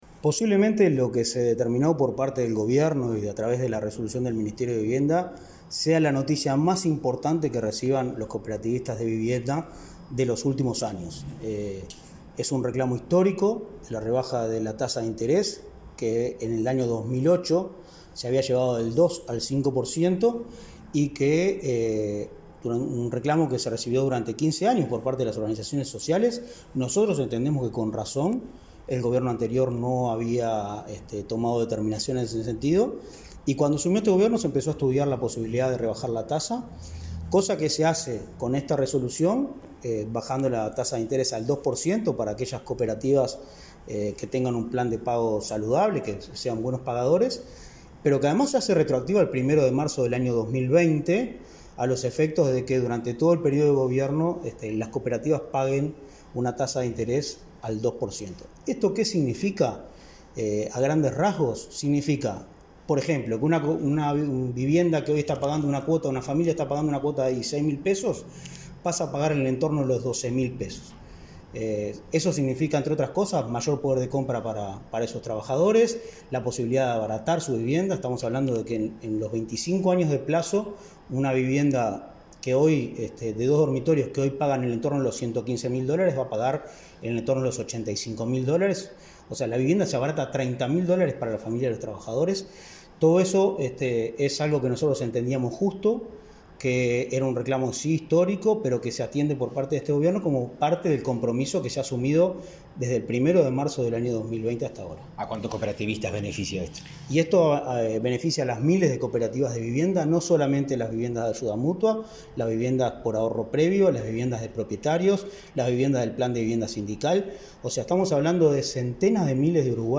Entrevista al director de Inacooop, Martín Fernández
El director del Instituto Nacional del Cooperativismo, Martín Fernández, dialogó con Comunicación Presidencial sobre la reducción de la tasa de